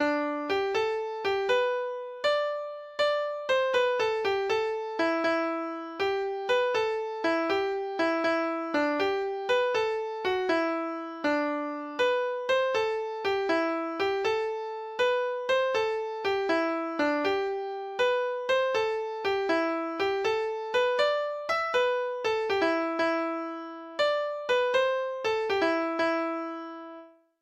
Lytt til data-generert lydfil